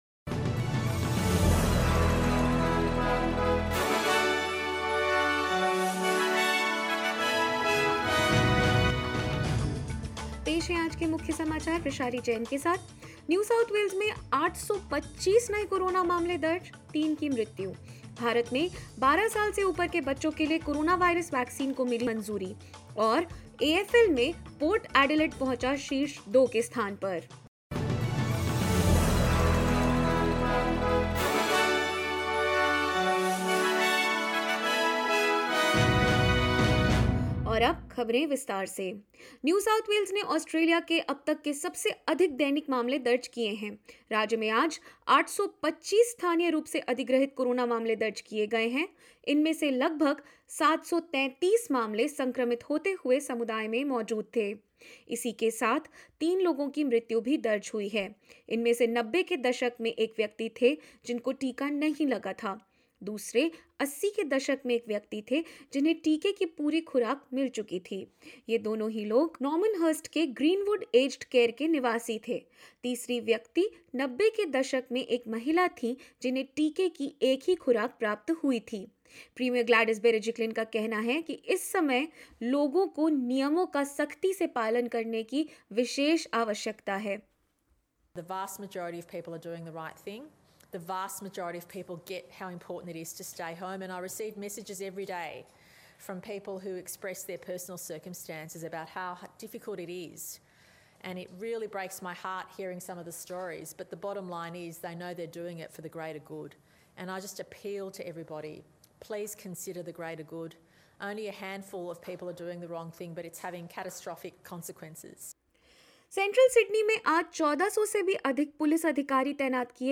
In this latest SBS Hindi News bulletin of Australia and India: Partial curfew to be implemented from Monday in 12 LGAs of concern in Sydney as NSW records 825 COVID-19 cases, 3 deaths; Lockdown extension in regional Victoria possible as virus leaks from city and more.